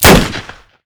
🌲 / midnight_guns mguns mgpak0.pk3dir sound weapon magnum
webley_01_REPLACEME.wav